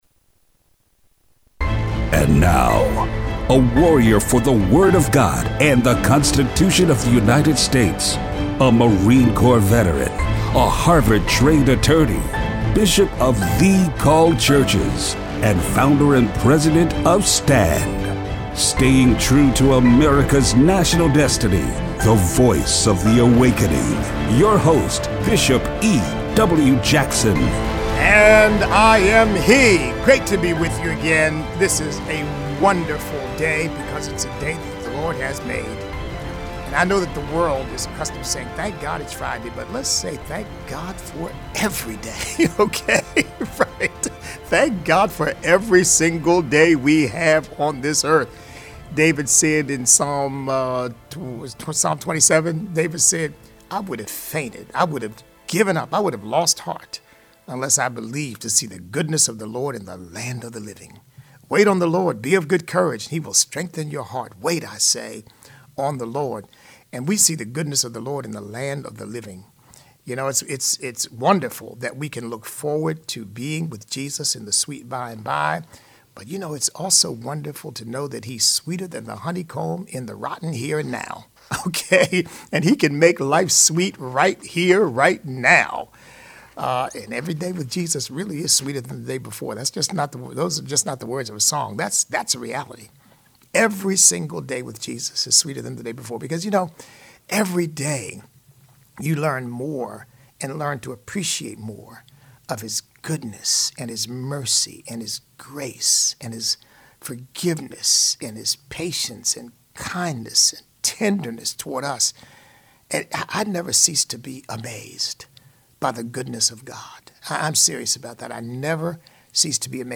Show Notes We open up the phone lines to hear from you!